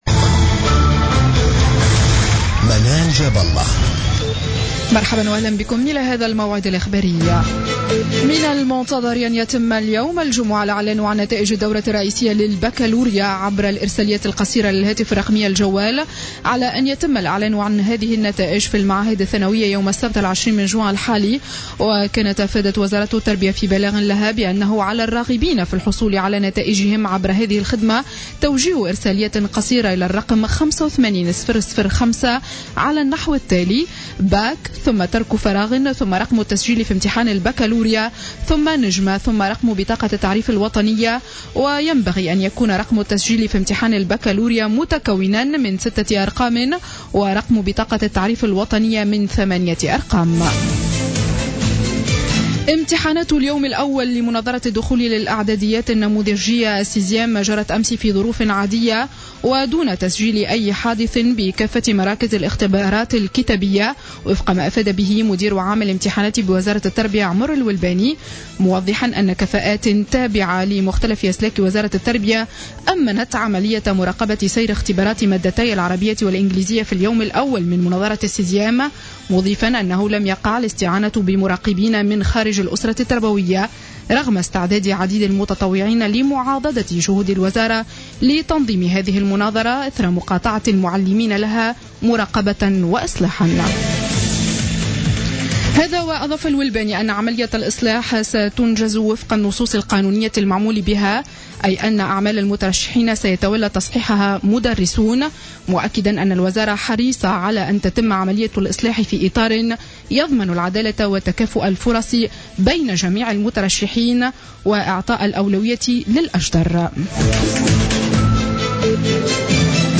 نشرة أخبار منتصف الليل ليوم الجمعة 19 جوان 2015